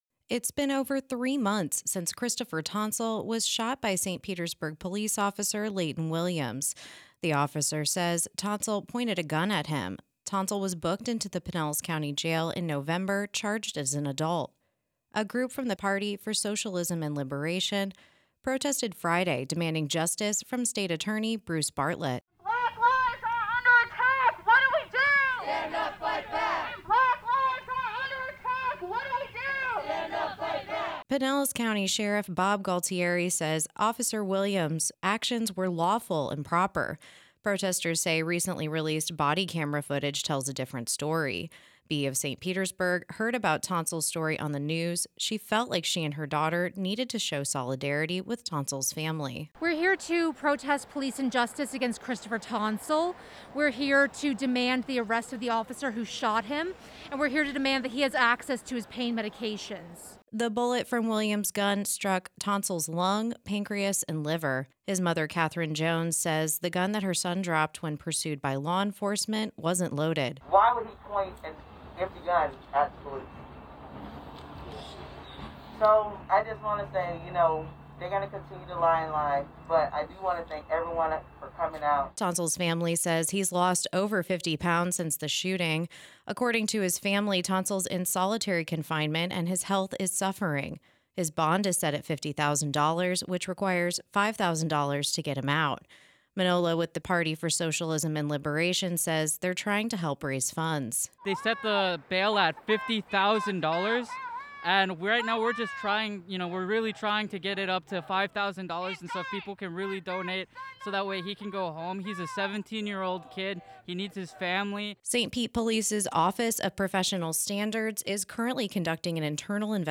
“When Black lives are under attack, what do we do? Stand up! Fight back! When Black lives are under attack, what do we do? Stand up! Fight back!” the crowd chanted.